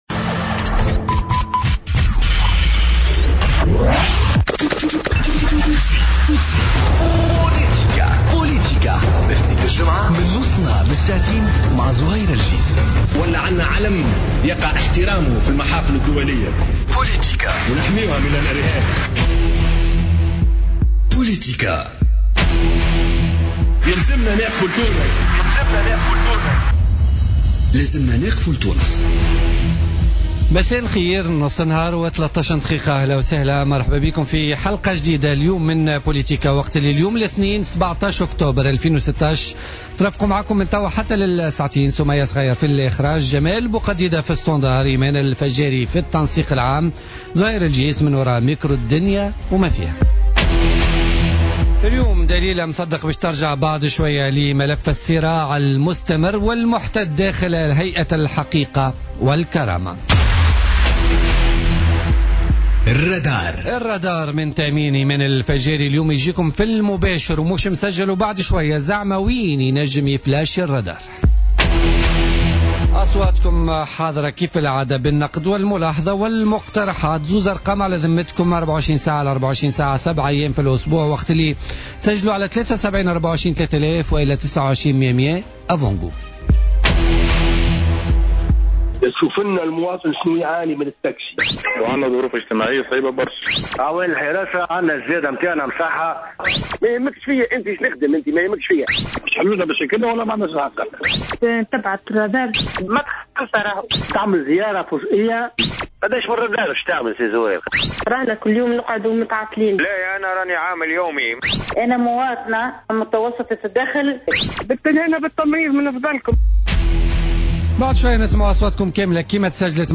Mustapha baazaoui, membre du conseil de l'Instance Vérité et Dignité, l'invité de Politica